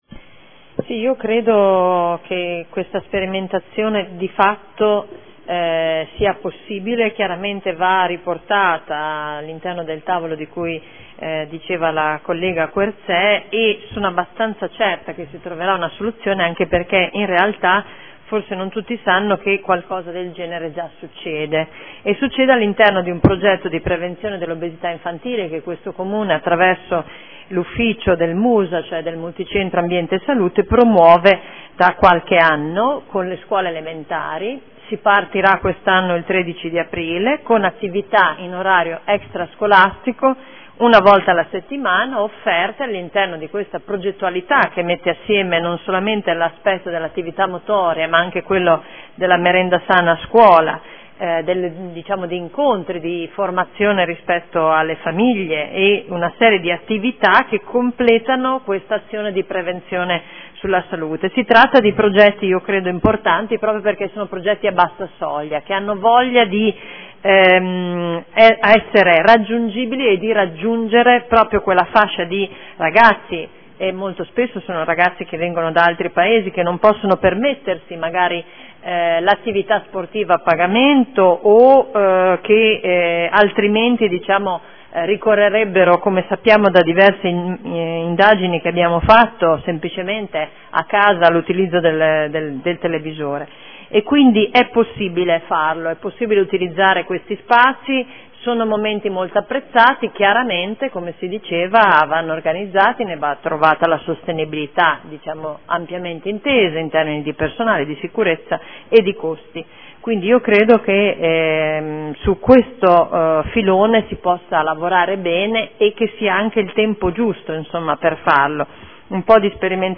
Simona Arletti — Sito Audio Consiglio Comunale
Dibattito